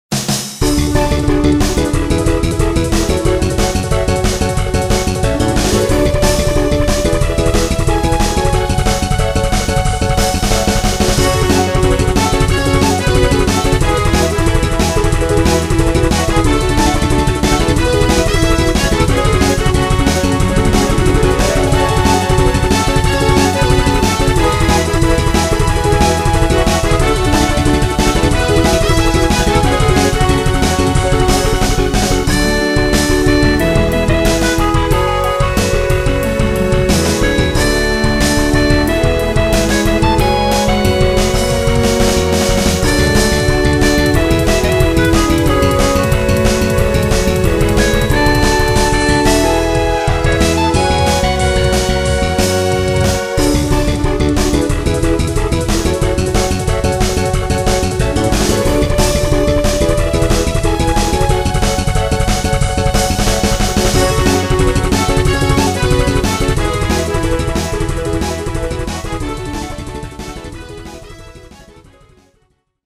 これらの曲は、全てドリームキャストを使って作りました。